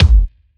• Small Reverb Kick G Key 547.wav
Royality free kick drum single shot tuned to the G note. Loudest frequency: 478Hz
small-reverb-kick-g-key-547-pzN.wav